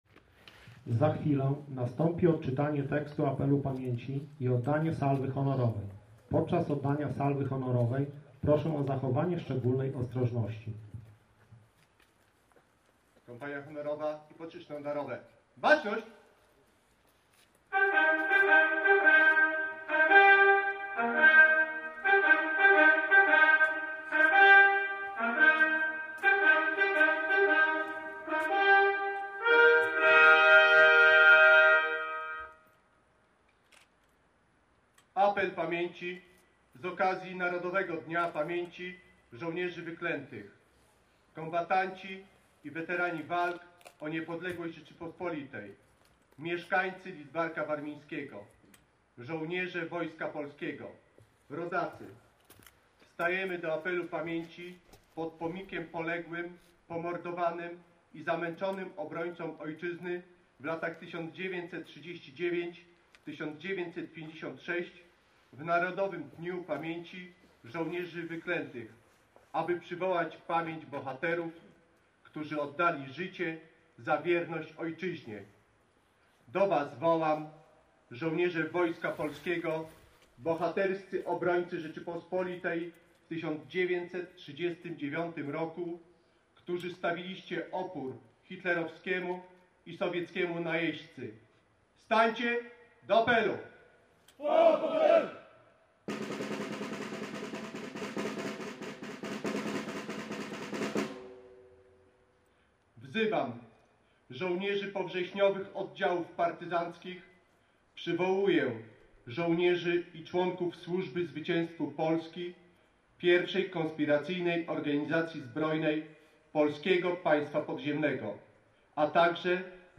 Apel Pamięci z okazji Narodowego Dnia Pamięci „Żołnierzy Wyklętych” w Lidzbarku Warmińskim
Apel-pamięci-Żołnierzy-Wyklętych-Lidzbark-Warmiński.mp3